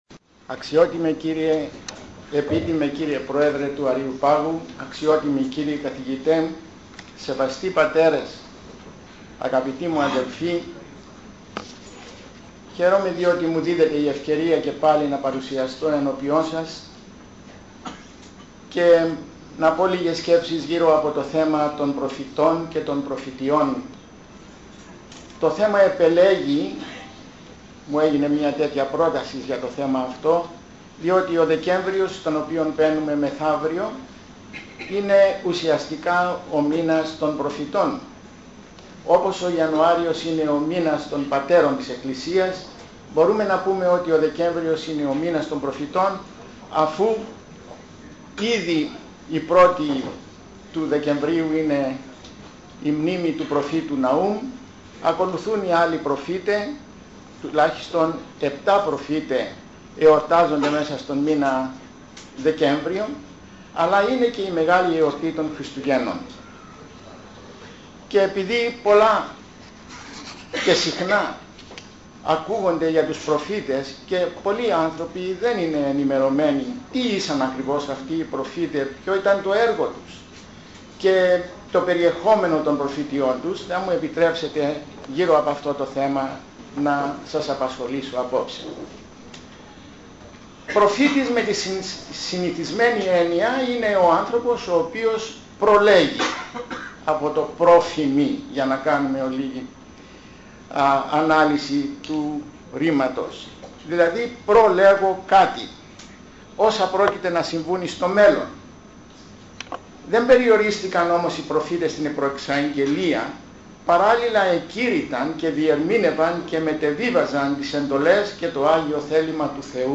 Ομιλία
Από εκδήλωση της Γ.Ε.Χ.Α. Θεσσαλονίκης στην αίθουσα της Αλεξ. Σβώλου 42 στις 29 Νοεμβρίου 2009.